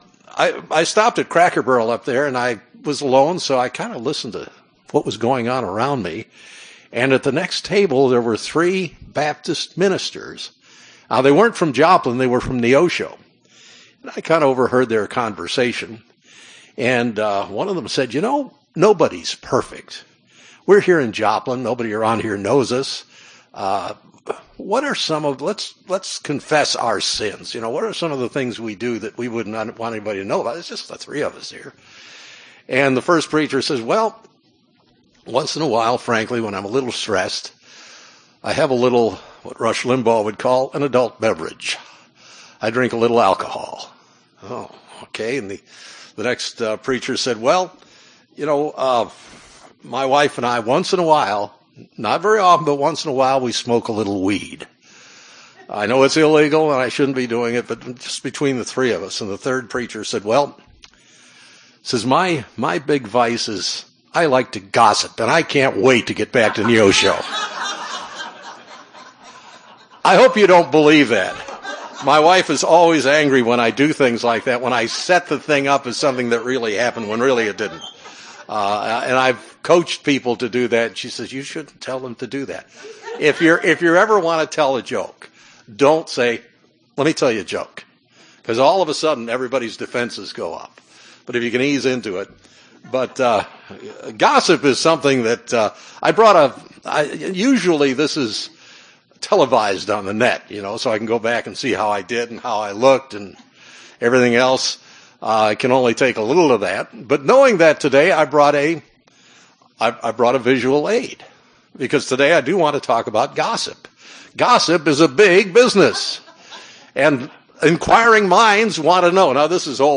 Given in Northwest Arkansas Springfield, MO
UCG Sermon Studying the bible?